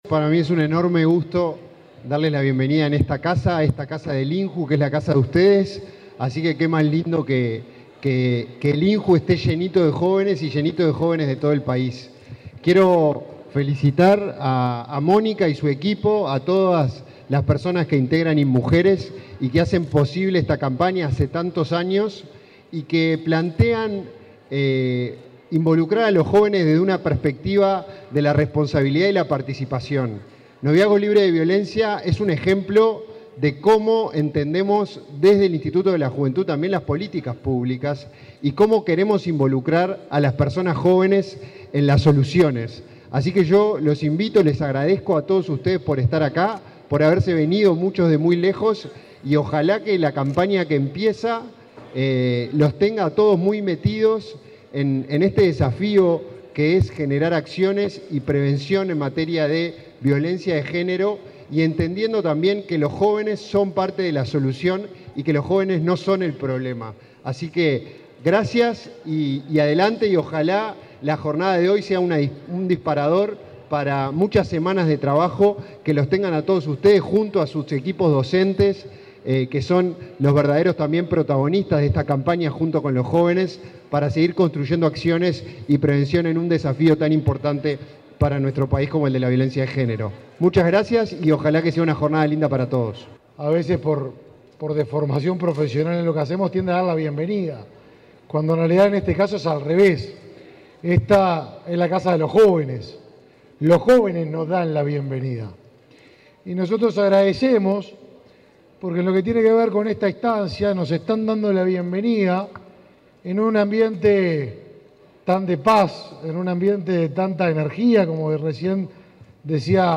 Conferencia de prensa por el lanzamiento de la campaña Noviazgo Libre de Violencia
Para el lanzamiento de la séptima edición, participaron el ministro de Desarrollo Social, Martín Lema, y el director de Instituto Nacional de la Juventud, Felipe Paullier.